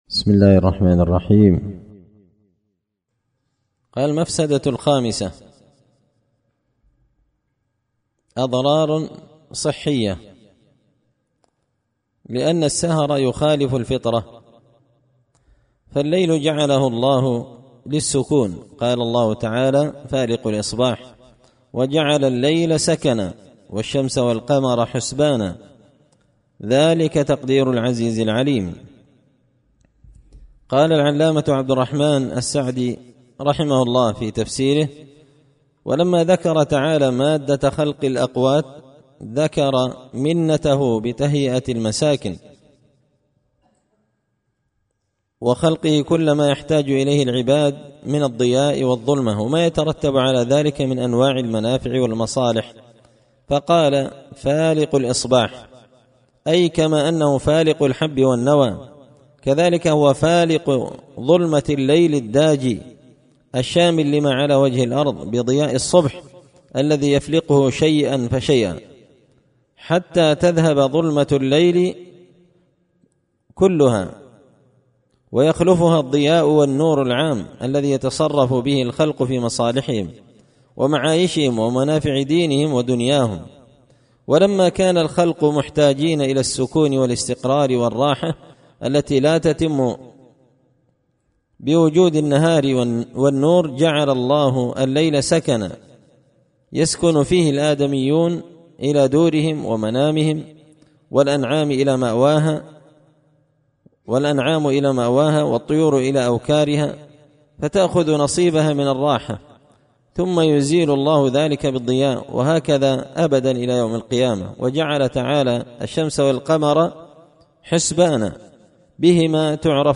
إمتاع النظر بأحكام السمر والسهر ـ الدرس الخامس عشر